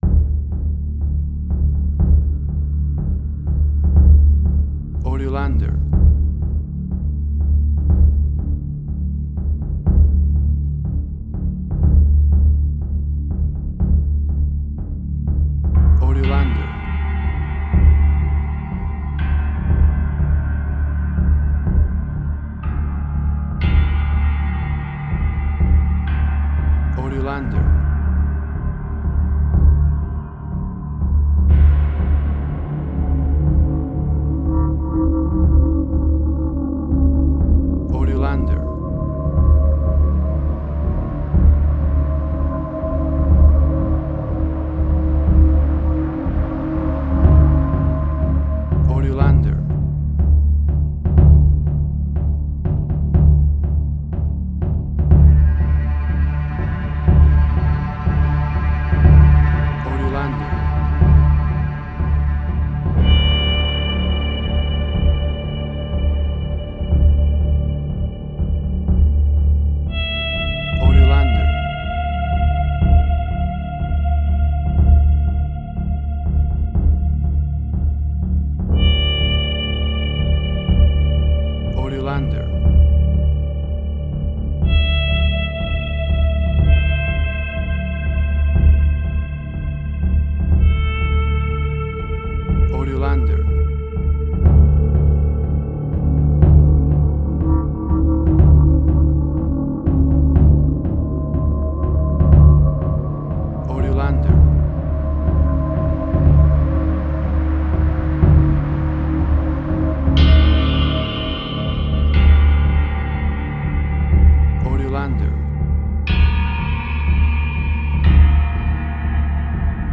Spaghetti Western, similar Ennio Morricone y Marco Beltrami.
Tempo (BPM): 123